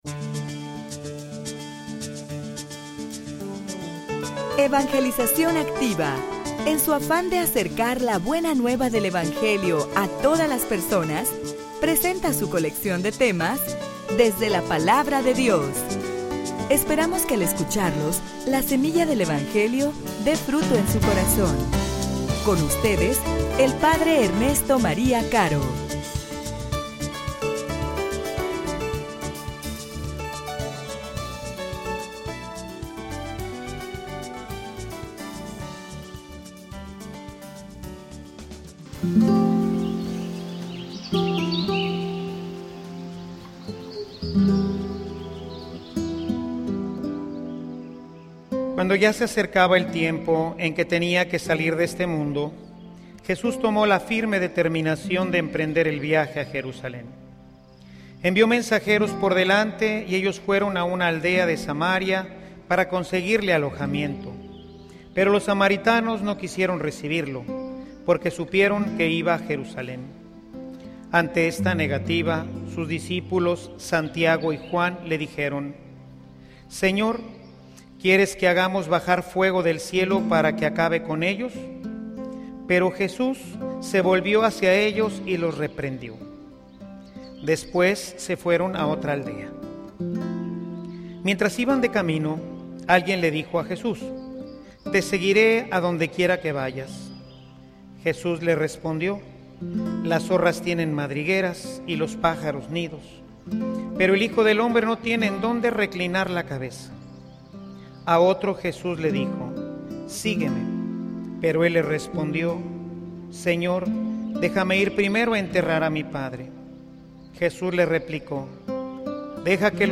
homilia_Condiciones_para_seguir_al_Maestro.mp3